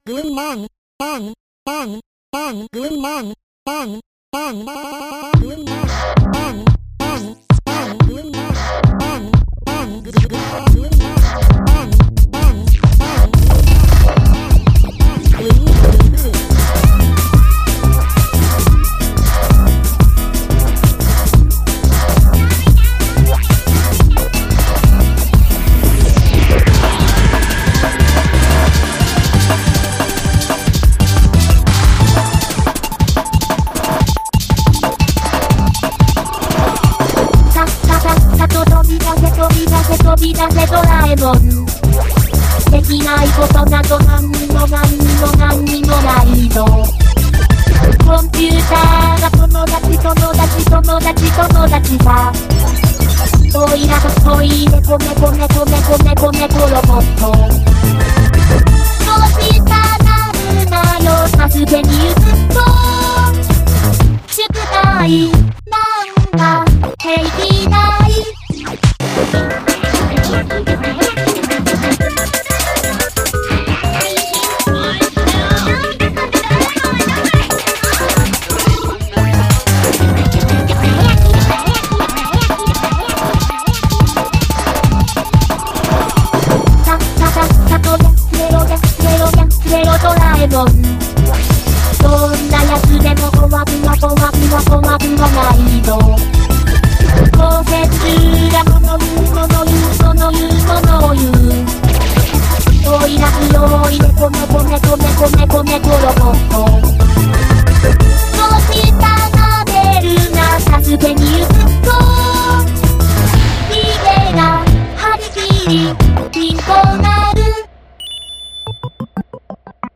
そのままでなくドラムンベース調にアレンジして
Vocaloid4 Rana v4
YMCK Magical 8bit Plug
VoiceOver Agnes
What about : Spanish Vocal Shouts Edition